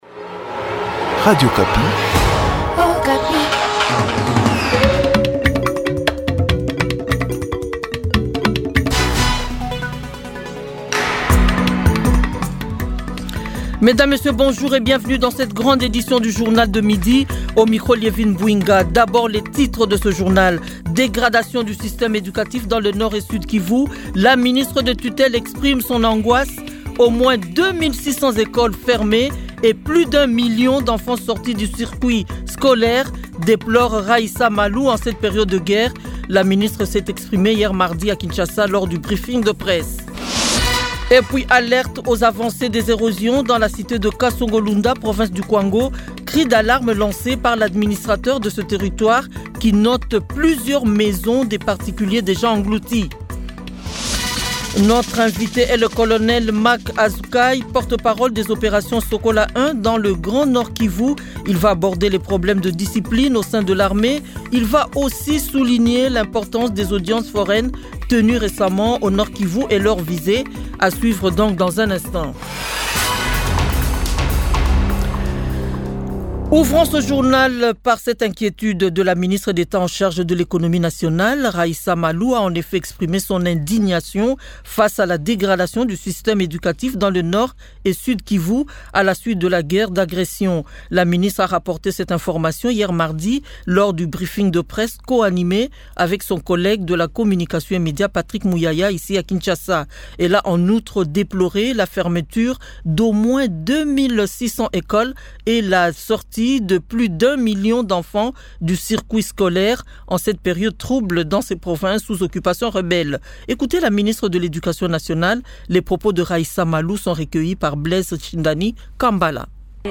Journal 12h mercredi 5 mars 2025